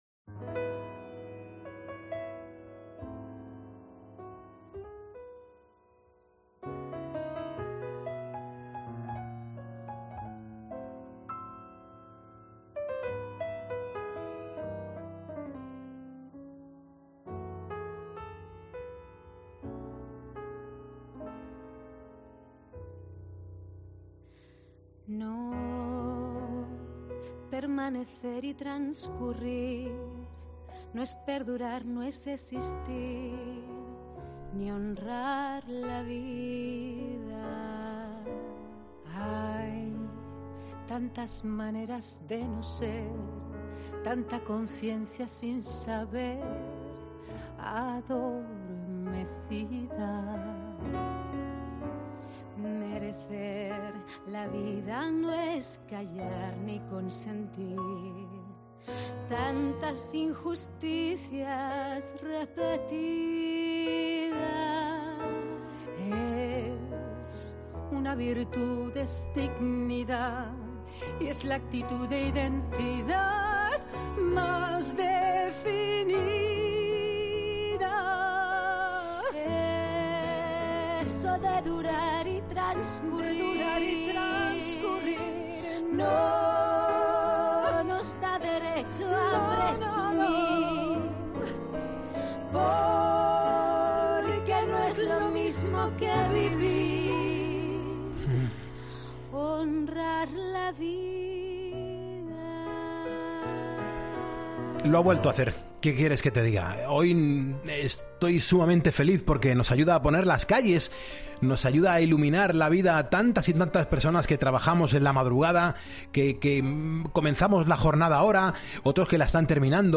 Estos días está presentando Mujeres de música 2 y la hemos invitado para disfrutar de su charla.